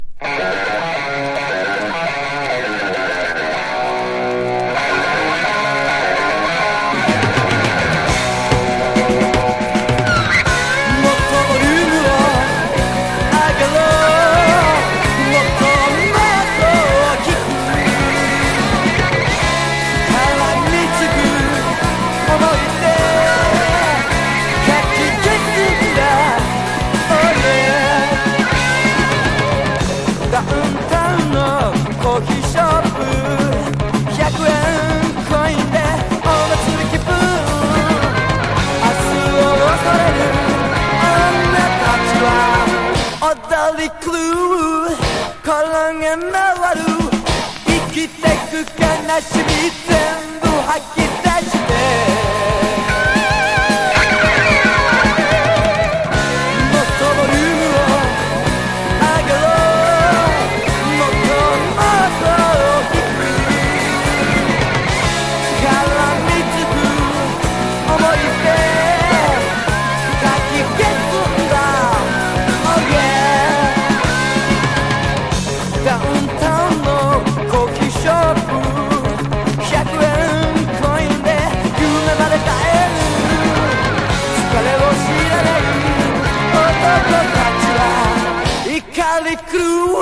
日本人70年代ハード・ロック・バンド
60-80’S ROCK